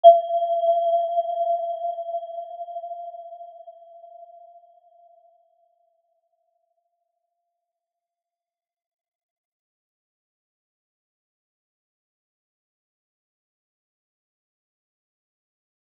Aurora-G5-mf.wav